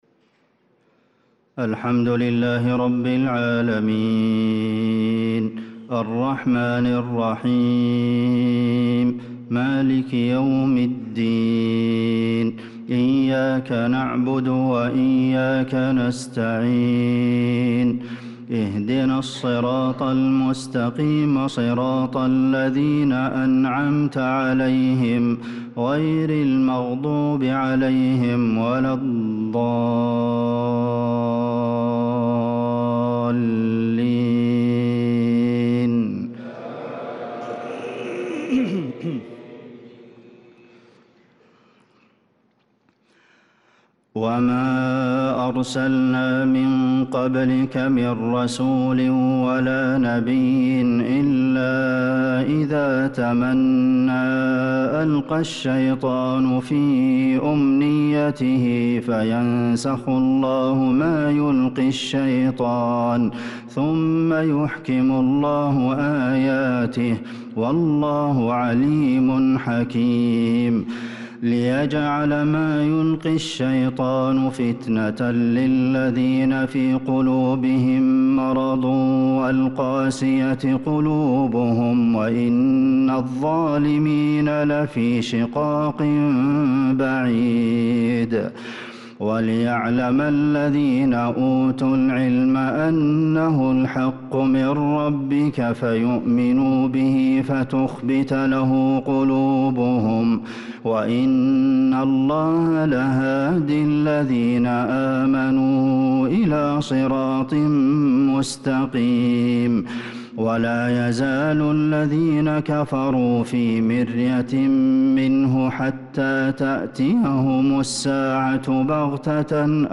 صلاة العشاء للقارئ عبدالمحسن القاسم 22 شوال 1445 هـ